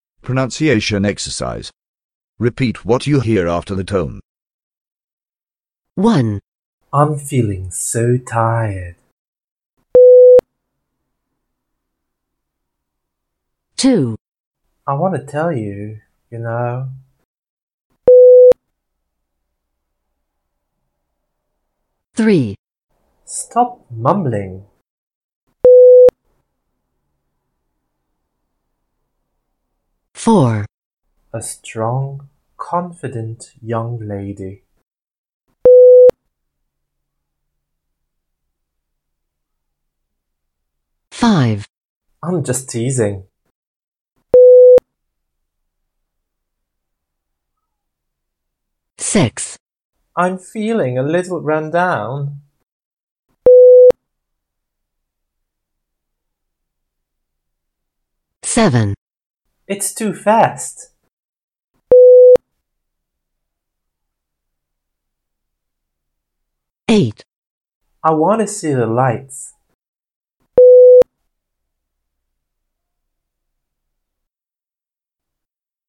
listen-and-repeat-02.mp3